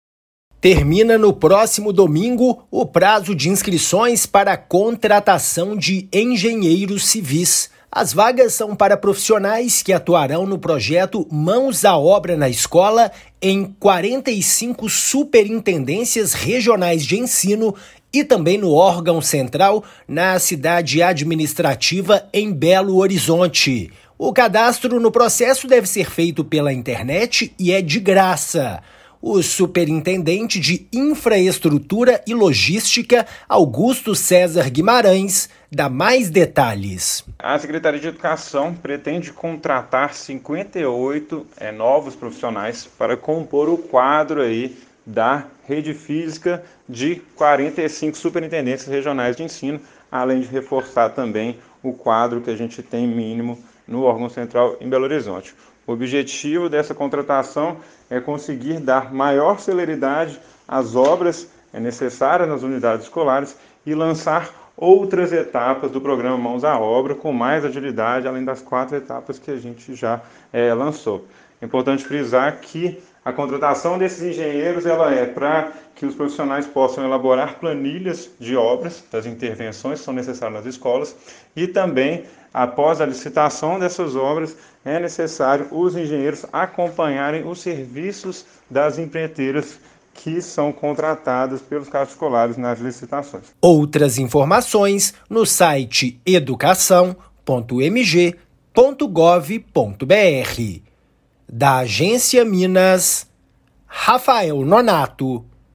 Profissionais vão atuar nas Superintendências Regionais de Ensino (SREs) e na administração central da Secretaria de Estado de Educação (SEE). Ouça a matéria de rádio.
MATÉRIA_RÁDIO_CONTRATAÇÃO_ENGENHEIROS.mp3